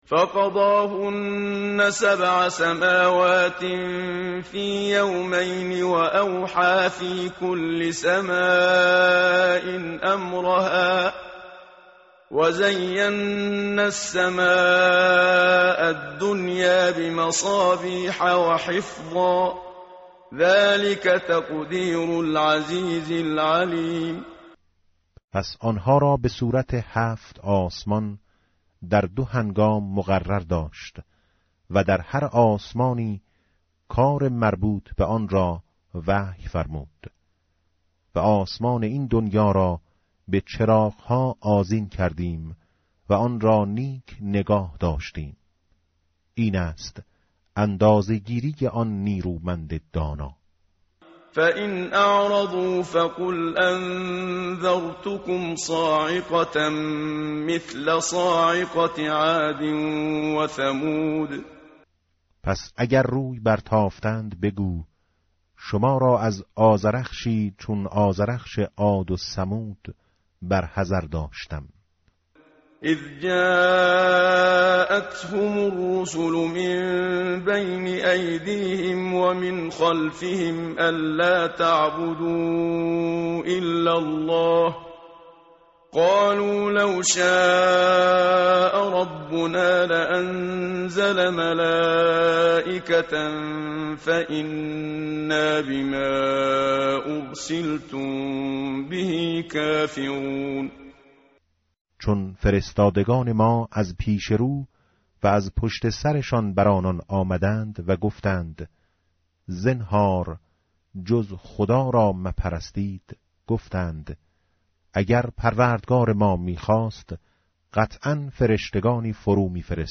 tartil_menshavi va tarjome_Page_478.mp3